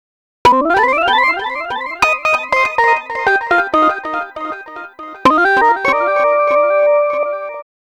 Astro 5 Organ Wet-C.wav